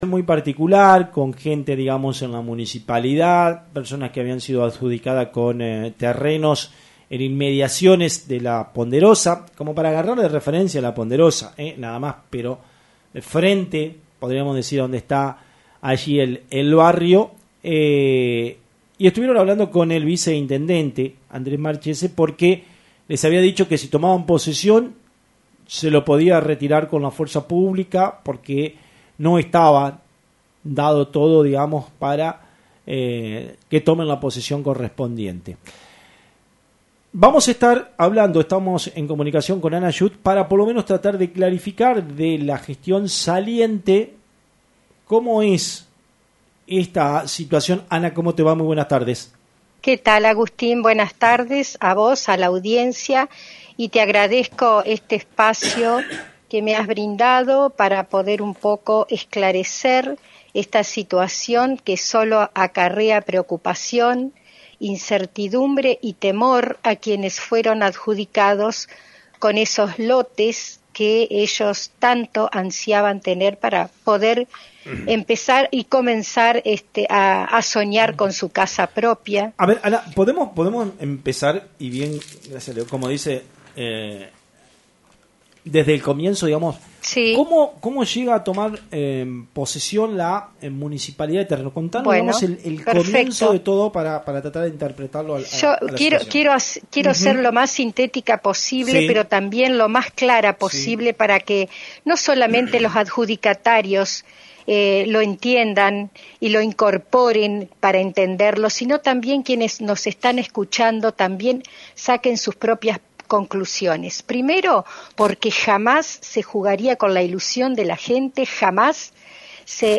la ex viceintendenta ,Ana Schuth intentó clarificar la situación en el programa “Radionoticias”